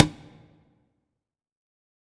CONGA 27.wav